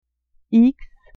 x ixe eeks